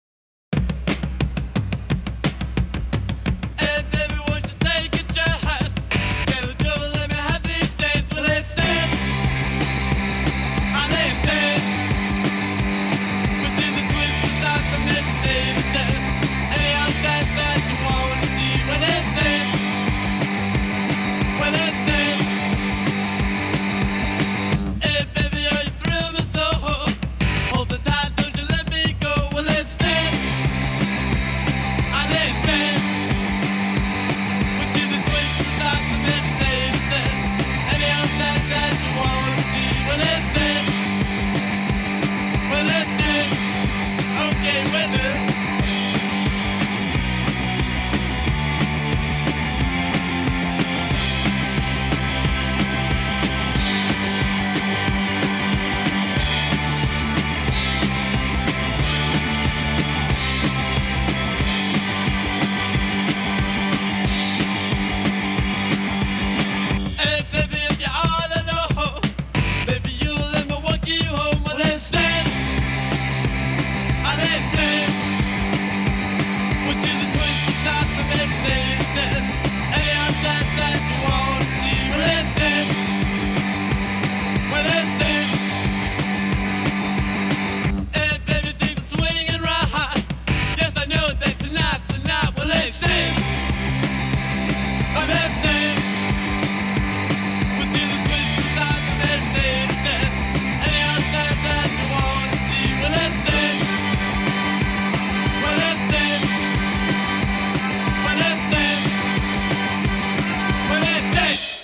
американская рок-группа